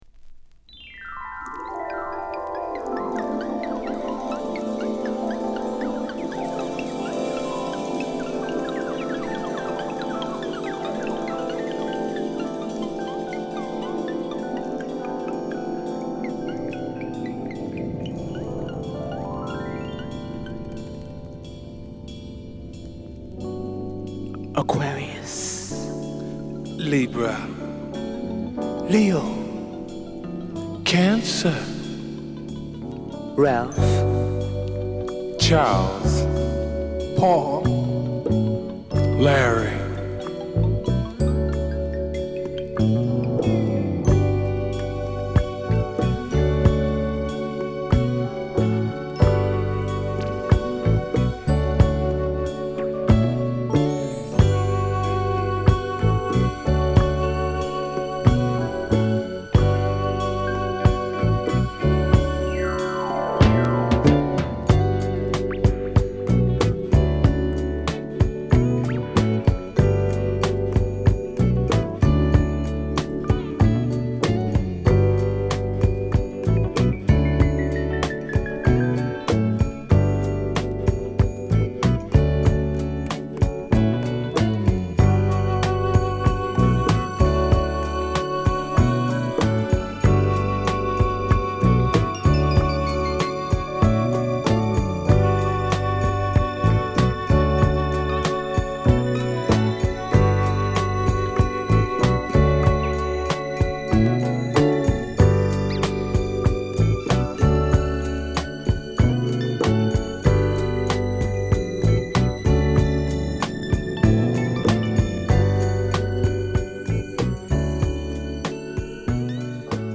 浮遊感溢れる優しいトラックにメンバーが順にマイクを回す名曲！